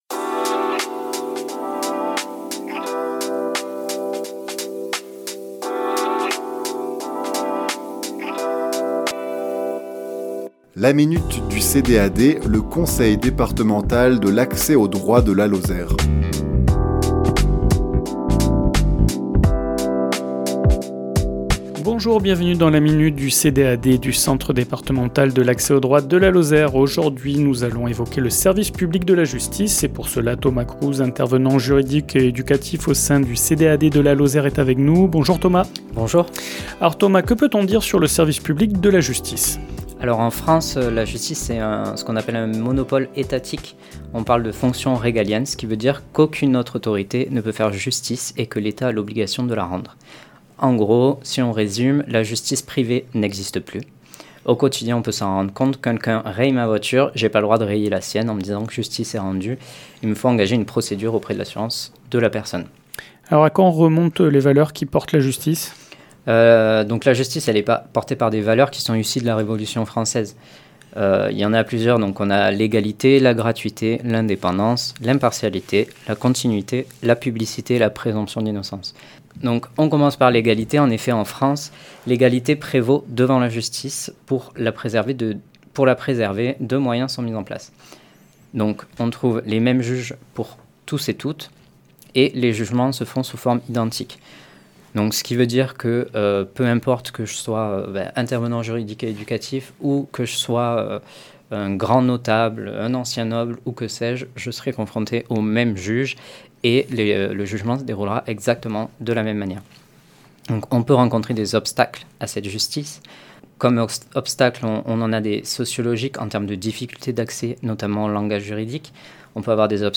Chronique diffusée le lundi 10 mars à 11h00 et 17h10